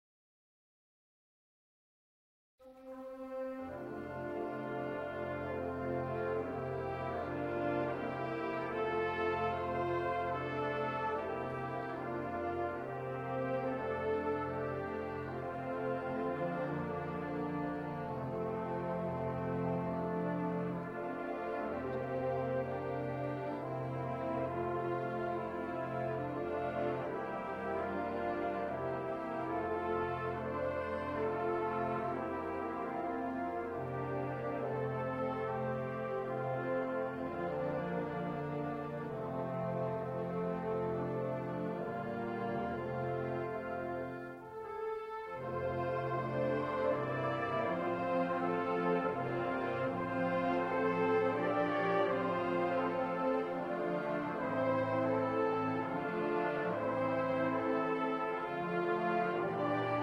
Trombone